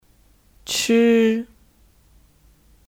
吃 (Chī 吃)